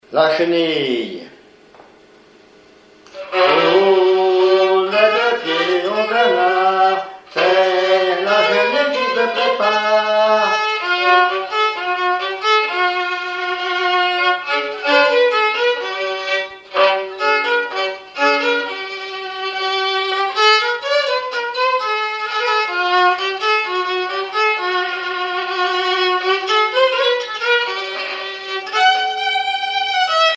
violoneux, violon,
danse : marche
Pièce musicale inédite